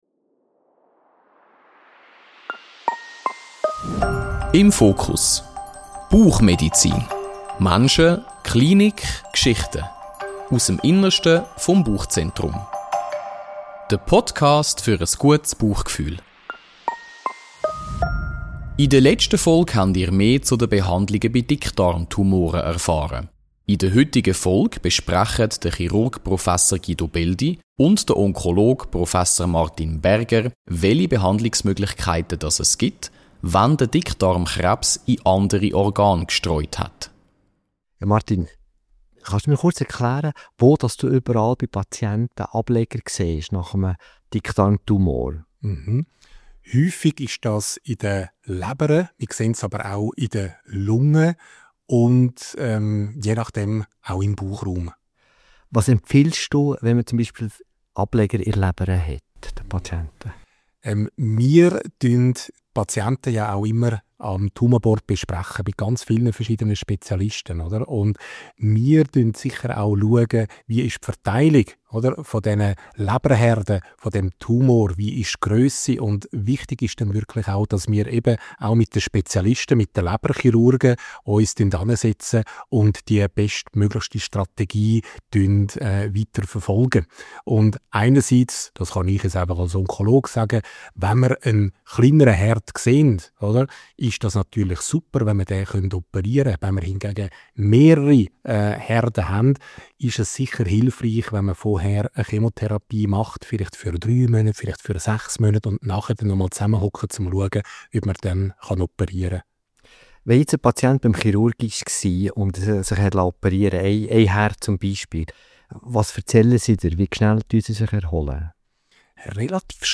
Dickdarmkrebs kompakt: Metastasen verstehen und behandeln – im Gespräch